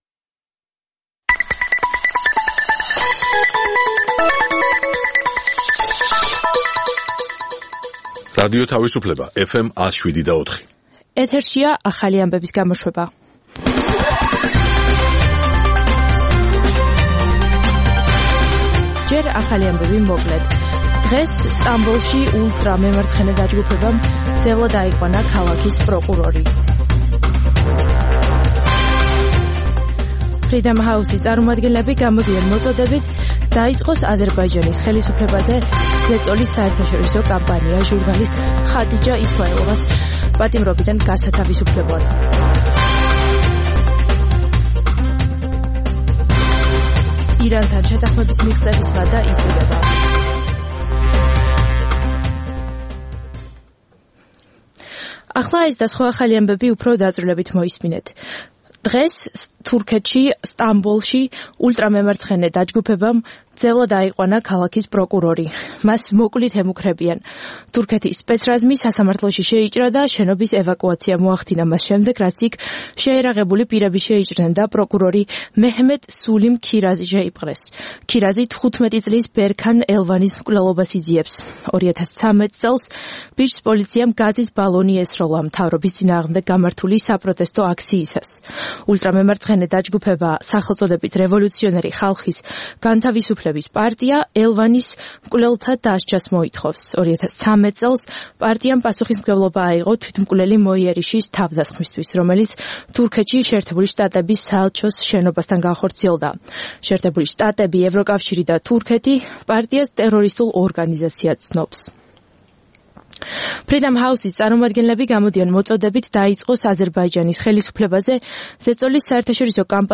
ახალი ამბები (რადიო თავისუფლება) + Music Mix ("ამერიკის ხმა")